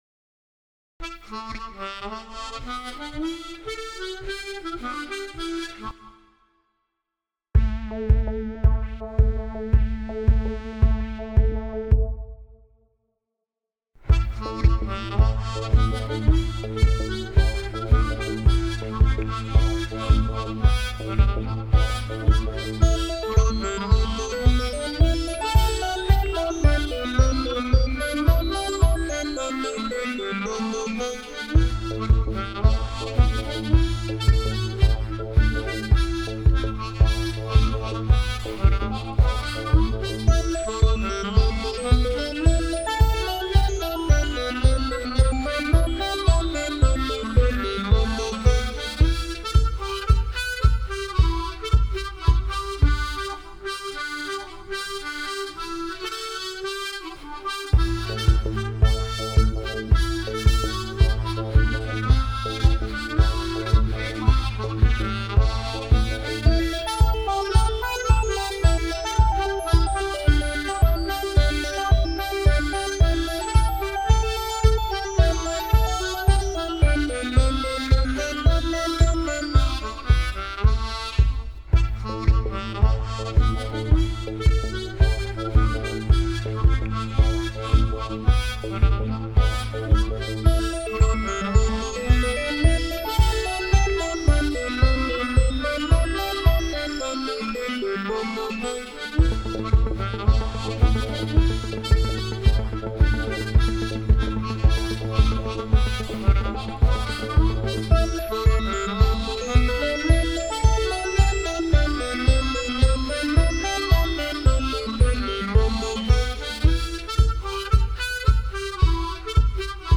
The Congress is a traditional Irish Reel, here played on a tremolo harmonica, folktronica (or eletrofolk) style, with some electronic accompaniment.
TheCongressReel_electro_folk_v2.ogg